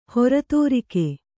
♪ hora tōrike